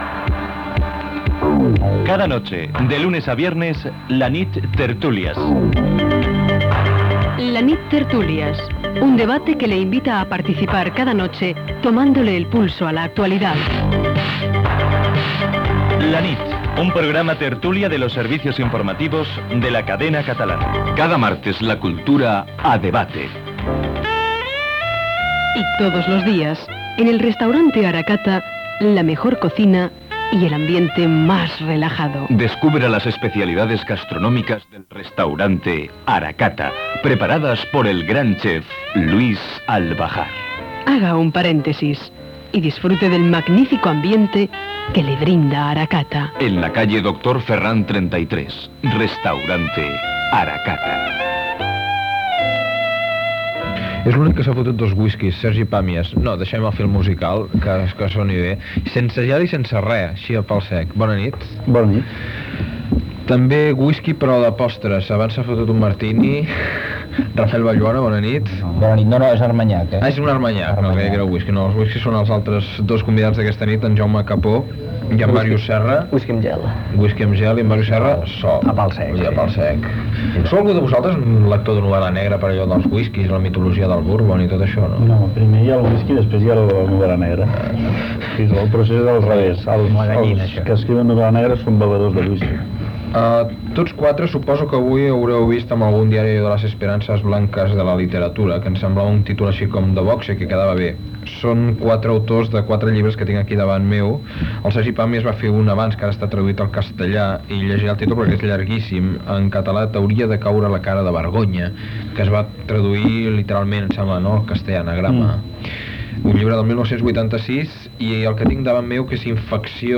Indicatiu del programa, publicitat, tertúlia cultural